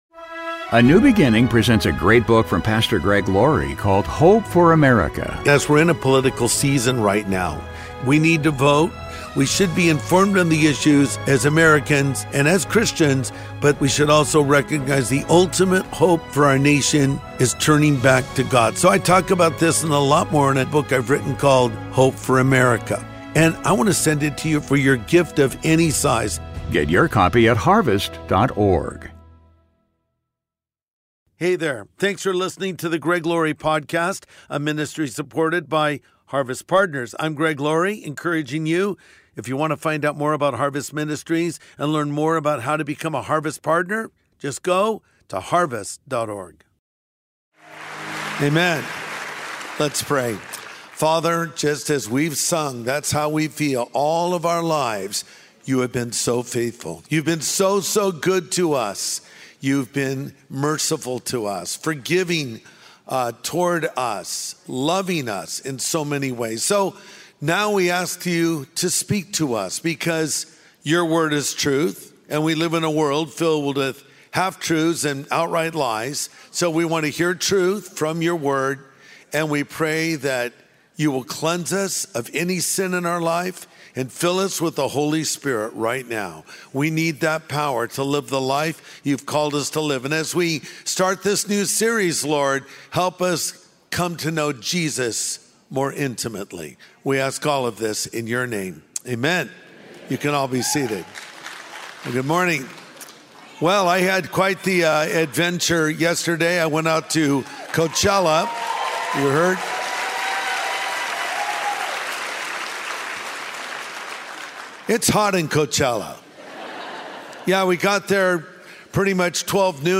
Jesus and the Doubter | Sunday Message Podcast with Greg Laurie